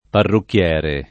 vai all'elenco alfabetico delle voci ingrandisci il carattere 100% rimpicciolisci il carattere stampa invia tramite posta elettronica codividi su Facebook parrucchiere [ parrukk L$ re ] s. m.; f. -ra — cfr. parrucca